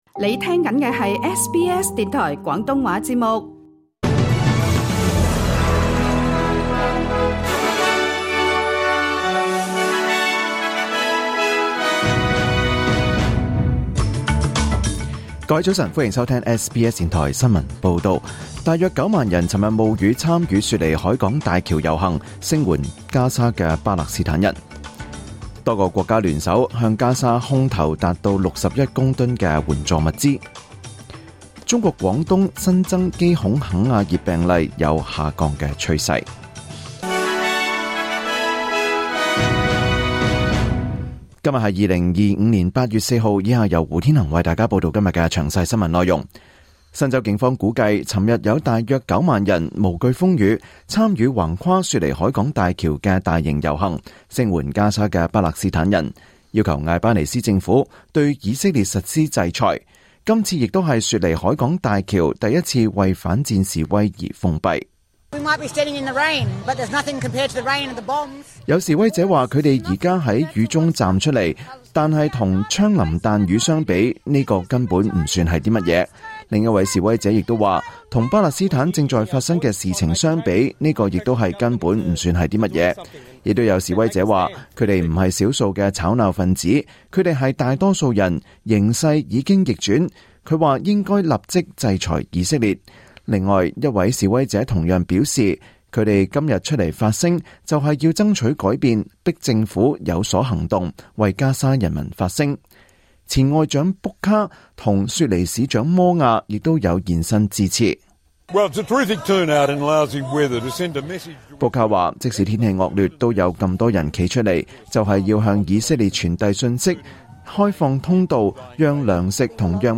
2025年8月4日SBS廣東話節目九點半新聞報道。